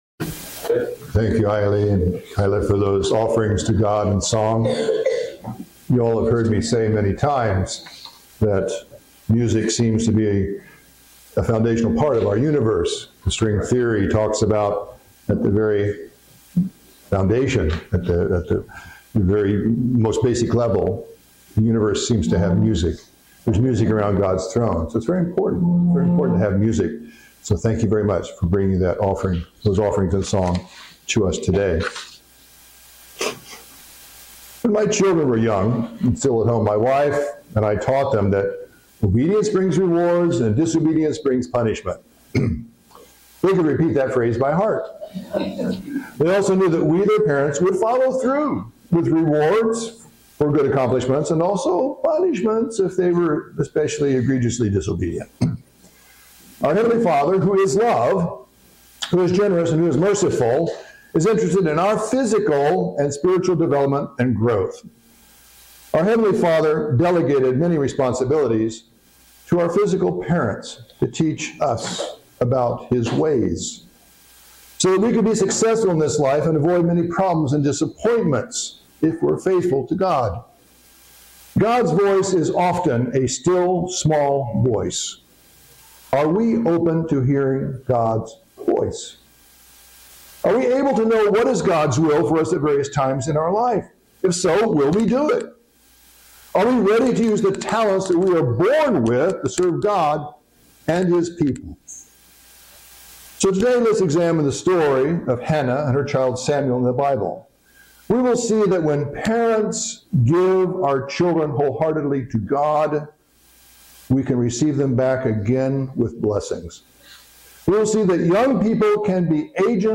Sermons
Given In Columbia, MD